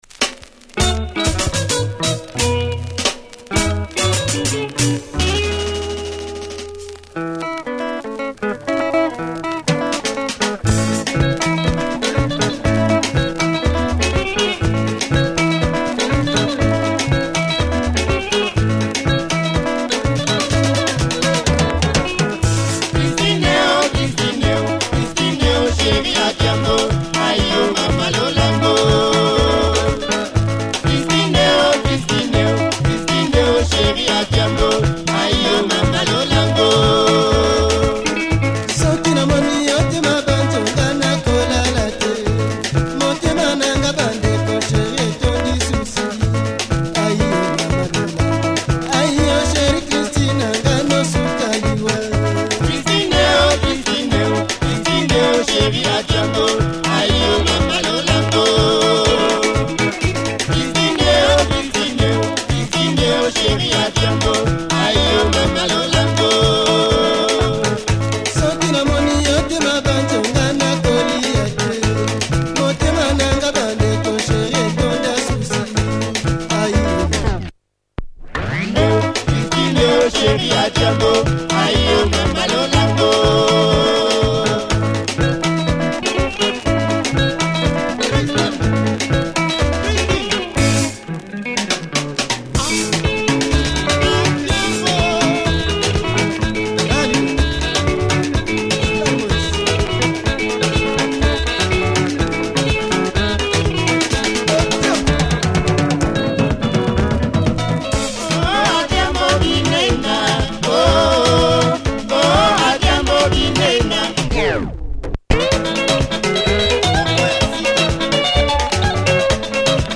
But still enjoyable. No jumps or skips!